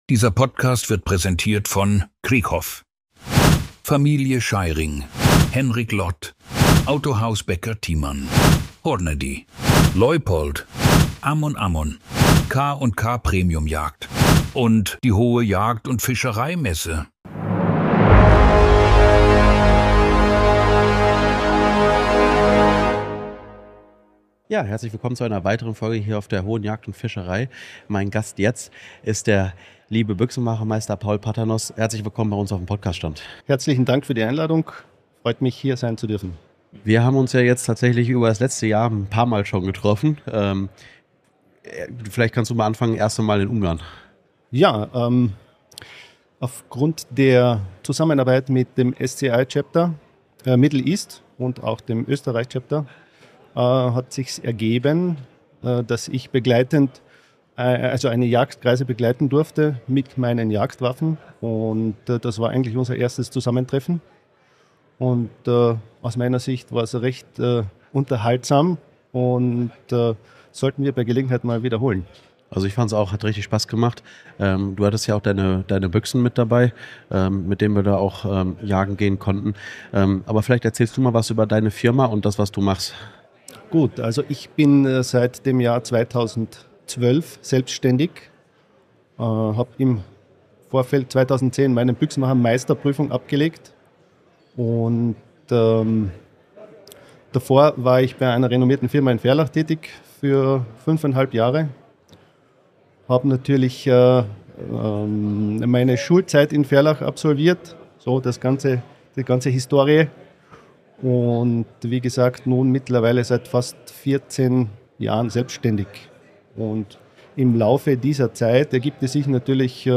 Gemeinsam sprechen die beiden über den traditionsreichen Waffenstandort Ferlach, der seit Jahrhunderten als Zentrum der europäischen Büchsenmacherei gilt – und darüber, warum klassische Handwerksbetriebe dort immer seltener werden.